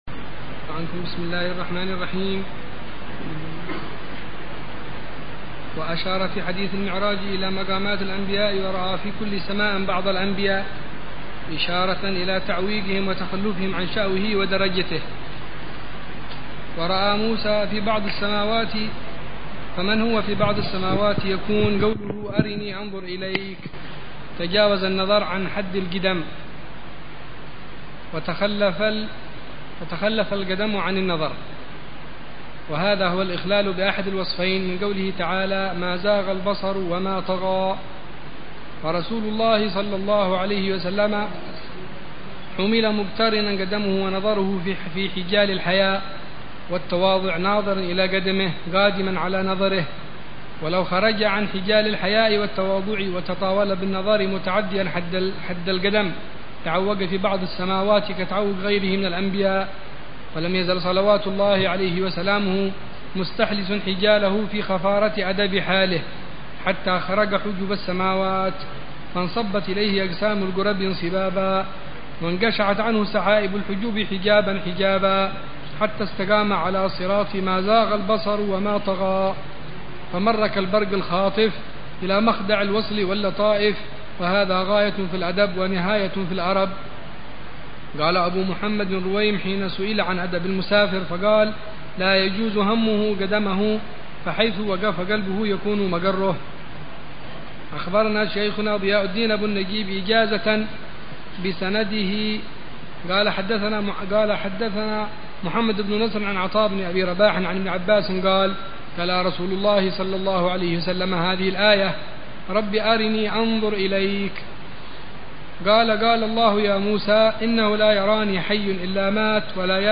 شرح لكتاب عوارف المعارف للإمام السهروردي ضمن دروس الدورة التعليمية الثانية عشرة والثالثة عشرة بدار المصطفى في صيف عامي 1427هـ و